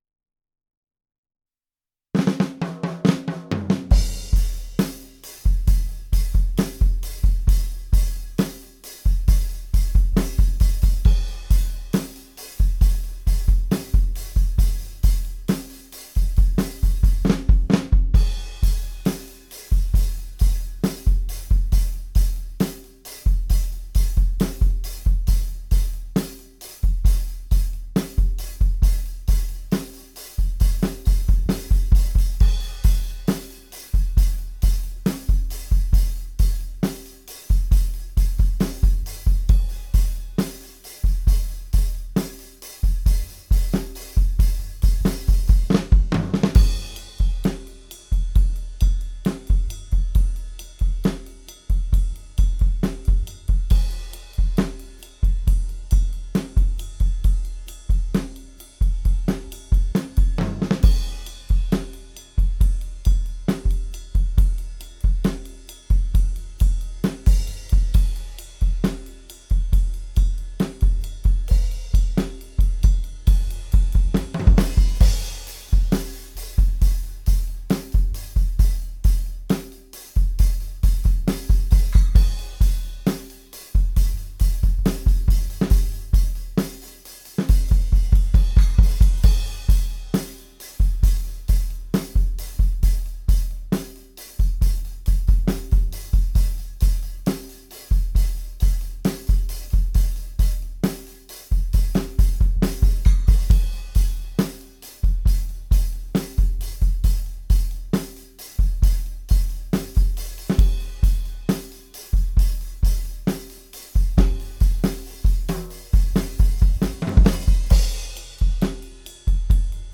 First time recording drums
View attachment Drum Test.mp3 I recorded this completely dry from the mixer. No EQ, no comp. I did however forgot to activate the HPF on the overheads, so I deployed the HPF plugin. I also used a gate with fast attack and a some what slow decay on the tom and floor tom.